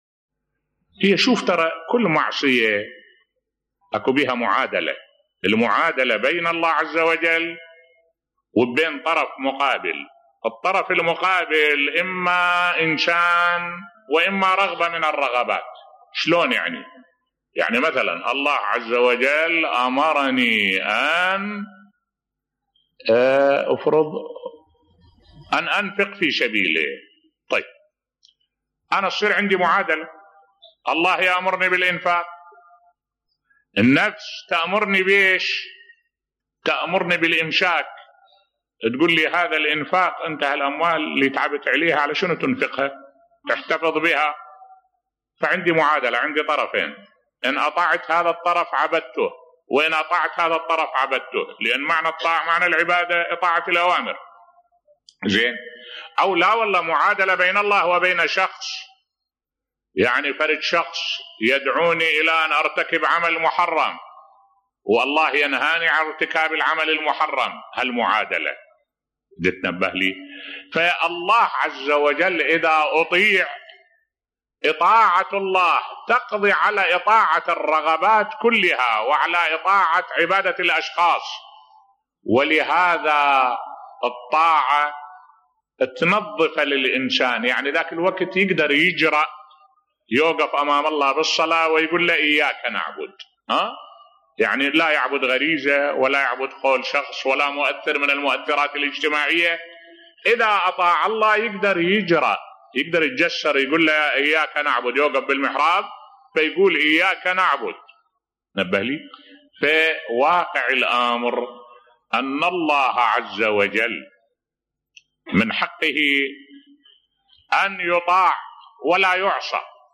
ملف صوتی حق الله في الطاعة بصوت الشيخ الدكتور أحمد الوائلي